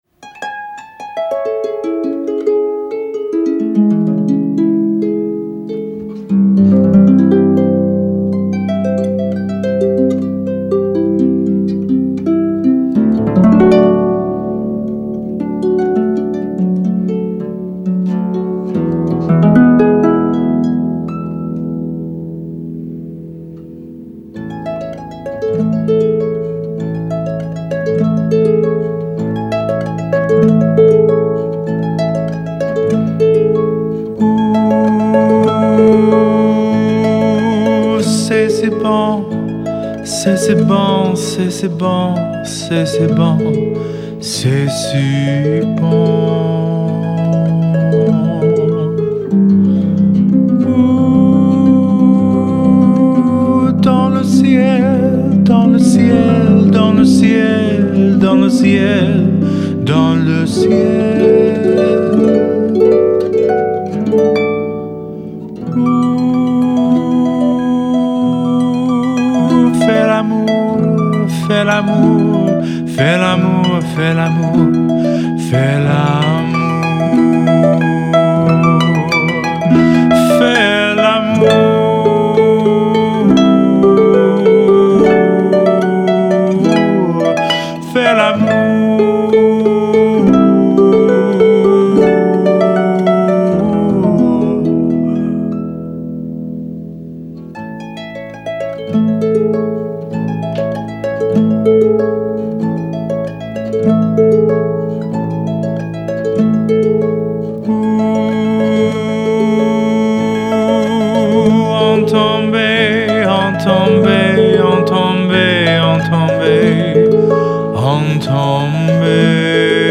Arranged and performed with harpist